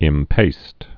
(ĭm-pāst)